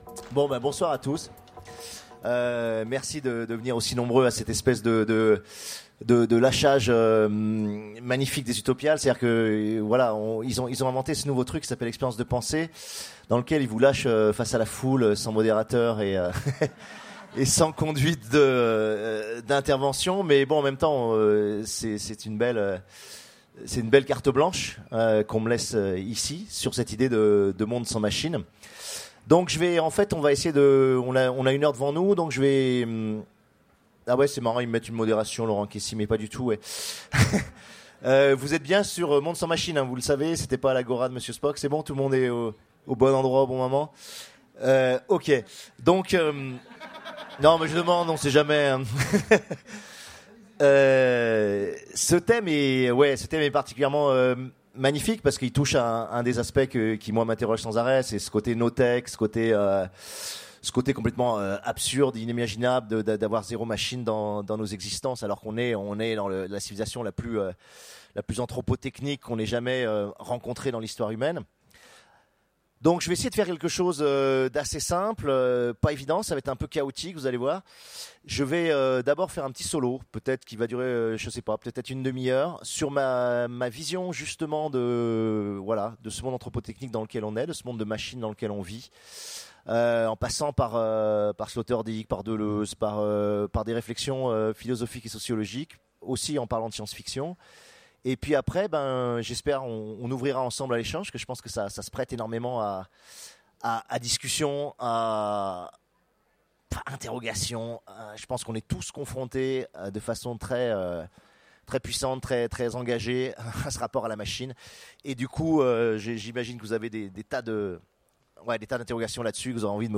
Télécharger le MP3 à lire aussi Alain Damasio Genres / Mots-clés Machine Conférence Partager cet article